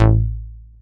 DOWN BASS A3.wav